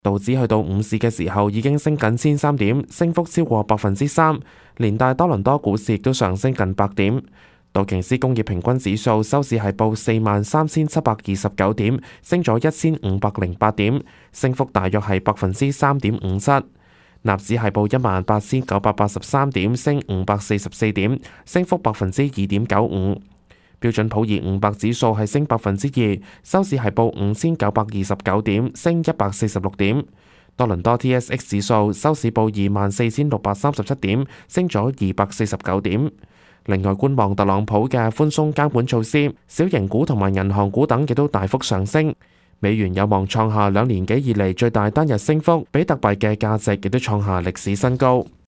news_clip_21208.mp3